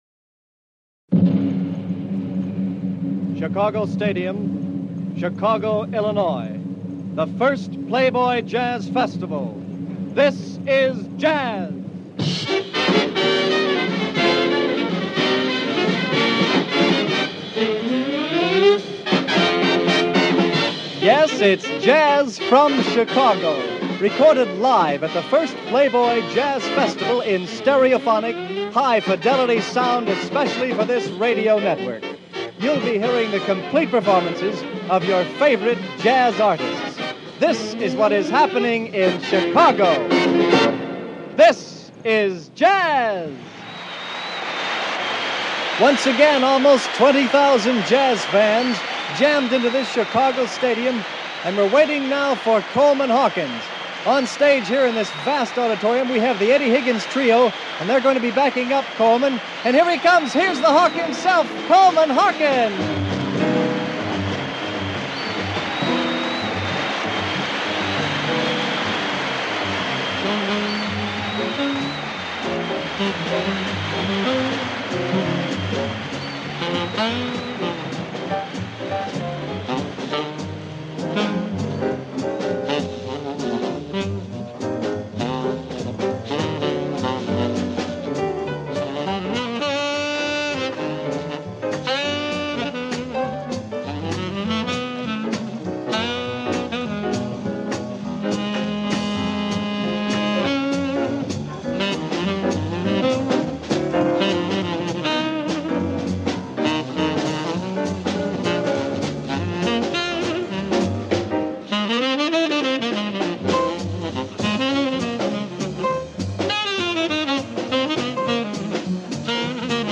jazz tenor saxophonist